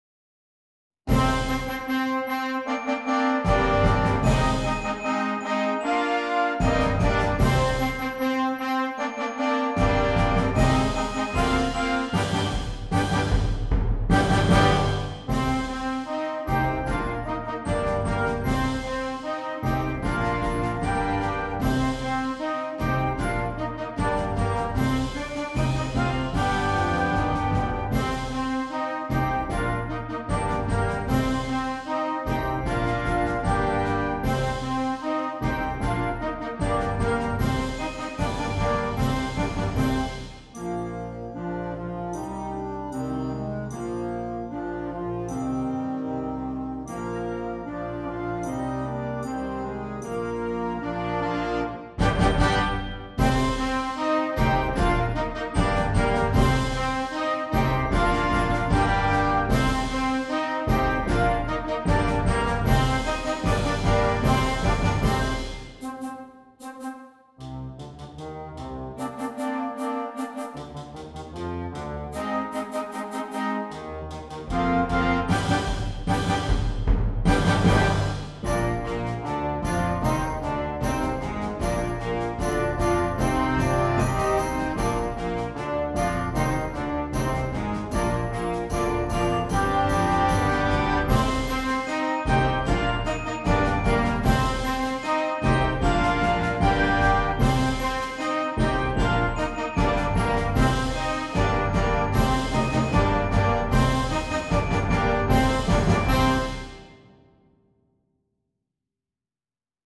Concert Band
a fun, high energy piece